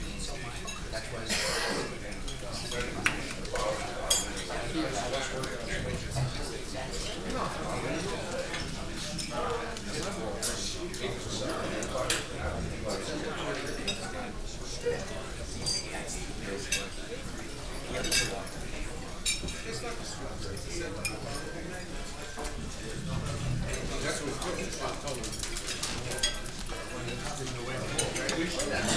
DinerAmbience VAL085101-glued.wav